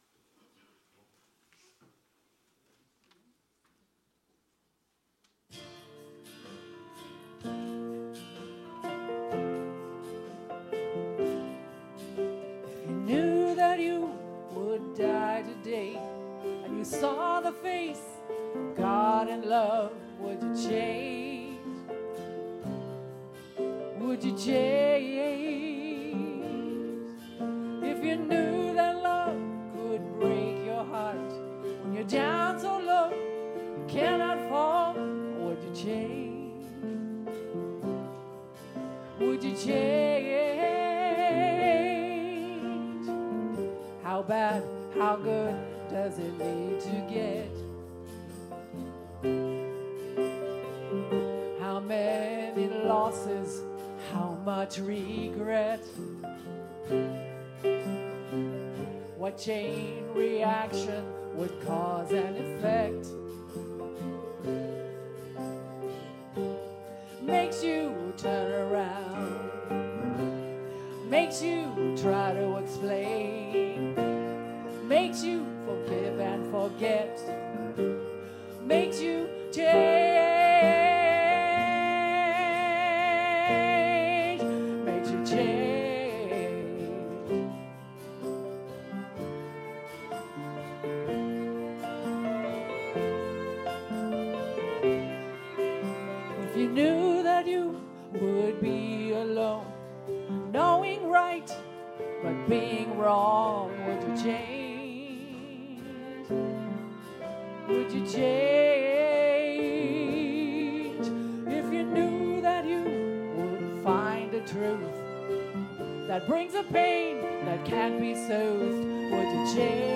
The audio recording (below the video clip) is an abbreviation of the service. It includes the Featured Song, Message, and Meditation.